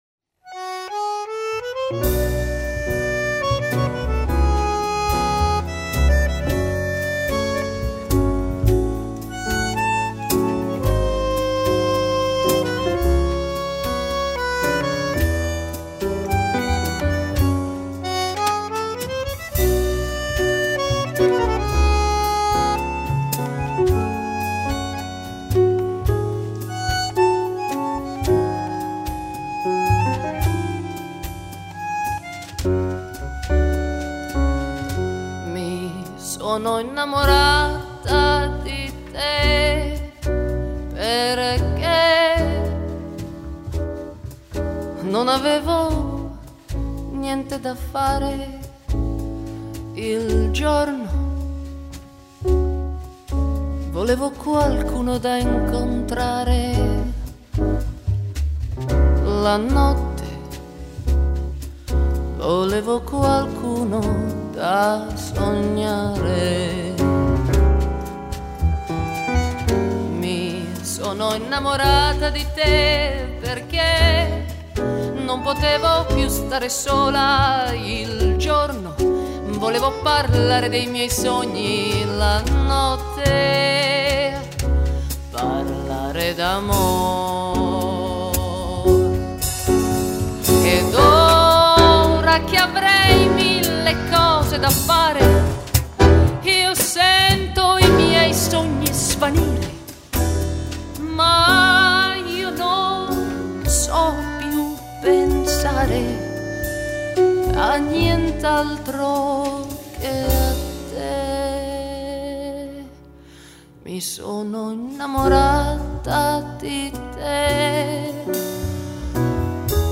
Luogo esecuzioneParma